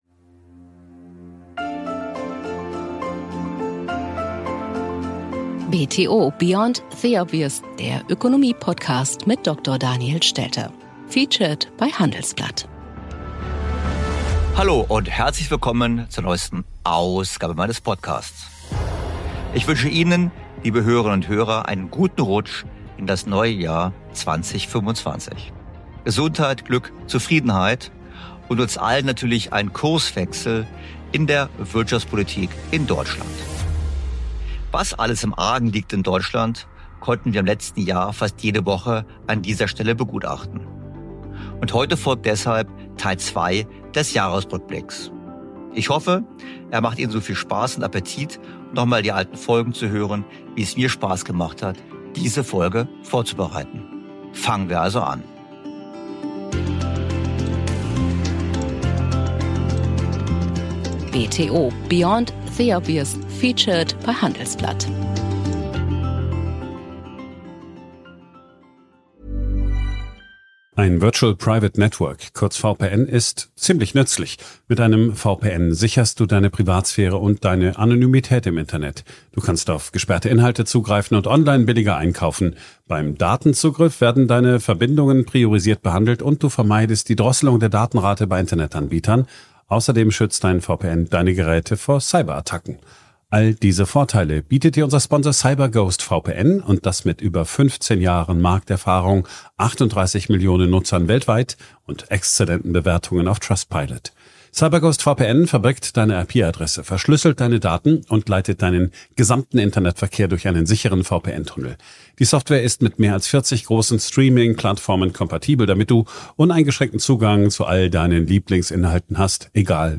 Doch zunächst ist jetzt traditionell die Zeit für einen Blick zurück auf überaus spannende 12 Podcast-Monate. Hier kommt der zweite Teil unseres Jahresrückblicks mit einer Auswahl der interessantesten Gespräche und Themen aus dem zweiten Halbjahr 2024.